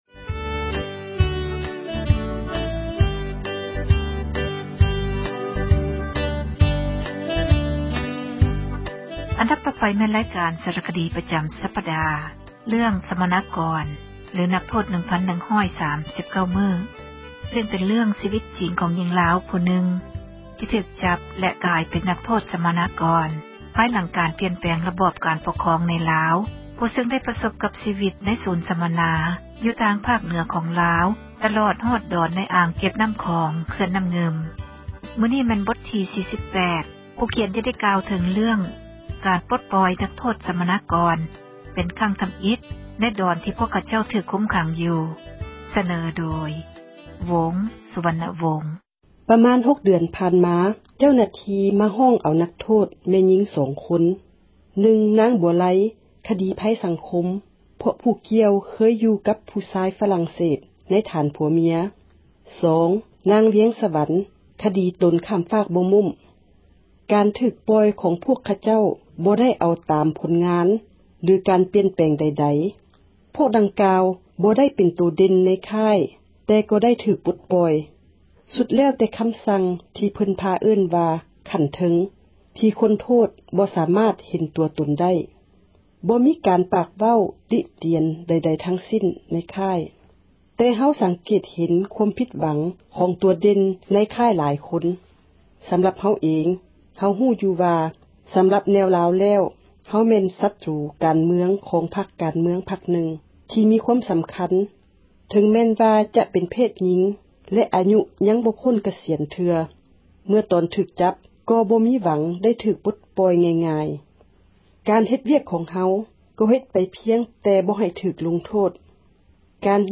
ຣາຍການ ສາຣະຄະດີ ປະຈຳ ສັປດາ ເຣື້ອງ ສັມມະນາກອນ ຫຼື ນັກໂທດ 1,139 ມື້ ບົດທີ 48 ຜູ້ຂຽນ ຈະໄດ້ ກ່າວເຖິງ ການປົດປ່ອຍ ນັກໂທດ ສັມມະນາກອນ ເປັນຄັ້ງ ທຳອິດ ໃນດອນ ທີ່ ພວກ ຂະເຈົ້າ ຖືກຄຸມຂັງ ຢູ່.